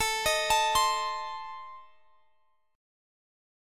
Adim Chord
Listen to Adim strummed